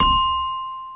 Xylophone C Major